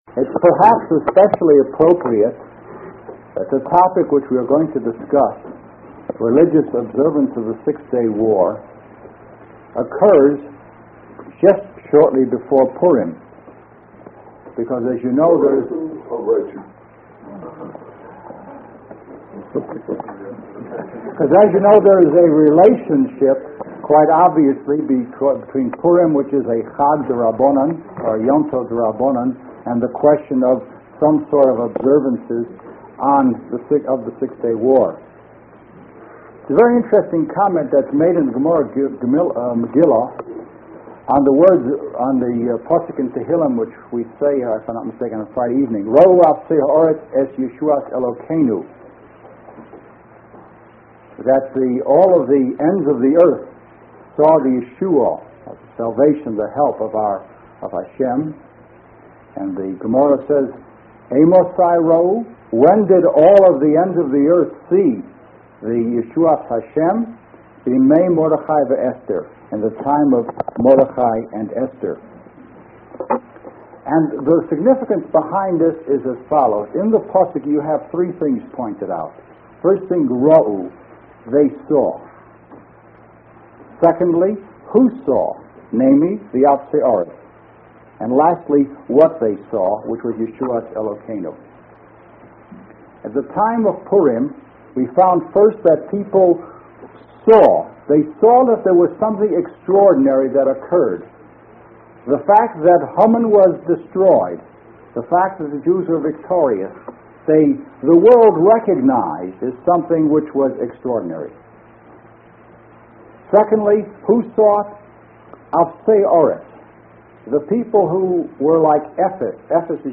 Rav Gifter speaking on Yom Ha’atzmaut part I.